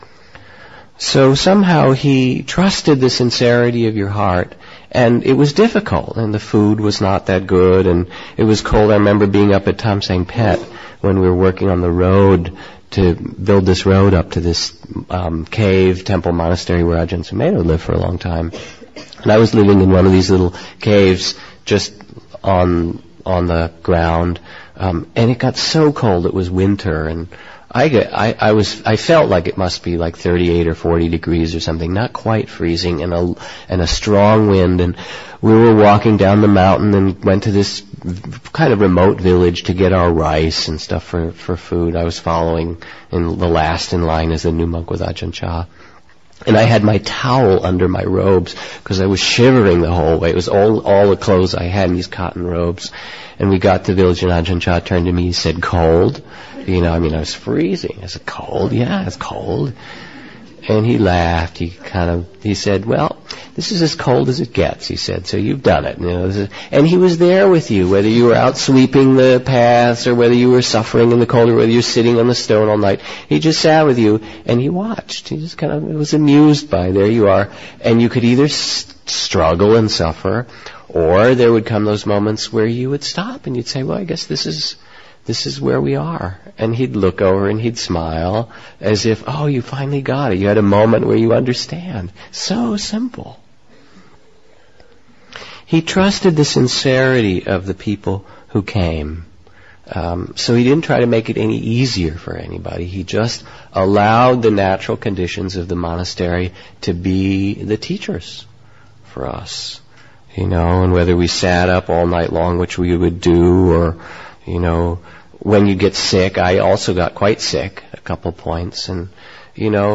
Quoted by Jack Kornfield.